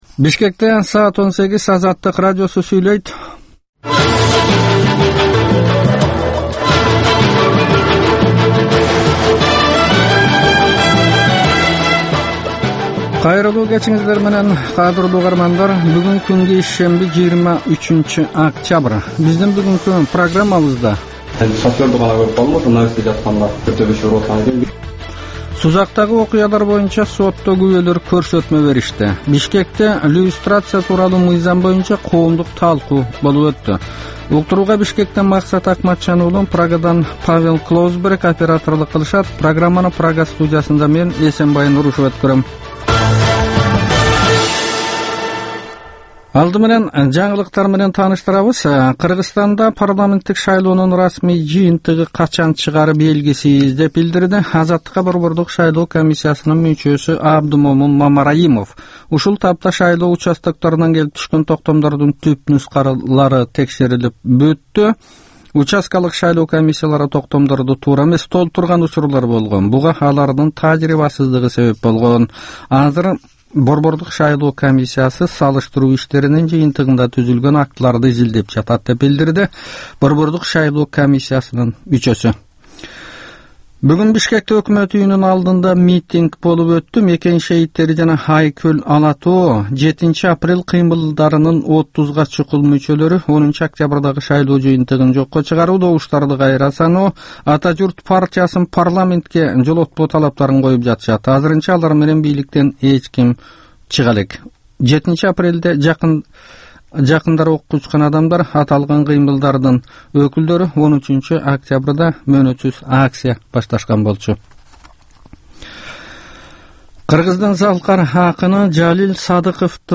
Кечки 6дагы кабарлар